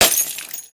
glassstep1.wav